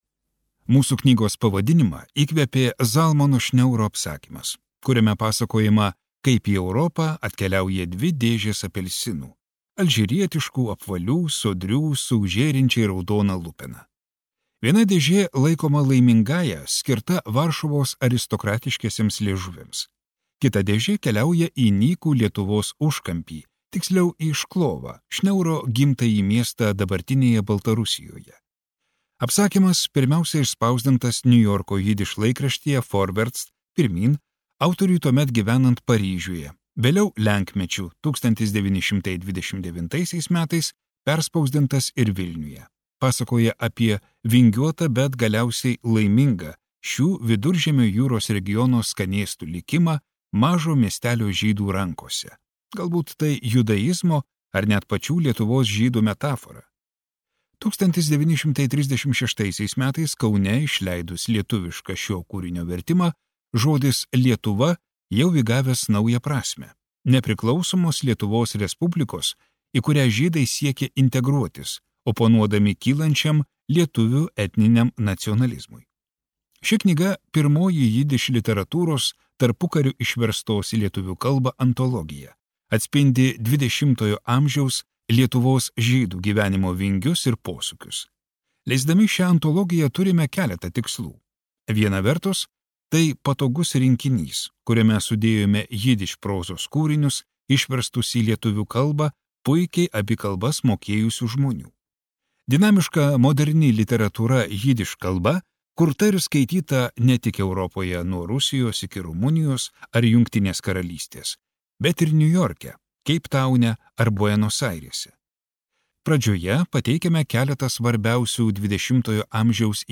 Skaityti ištrauką play 00:00 Share on Facebook Share on Twitter Share on Pinterest Audio Nemirtingasis apelsinas.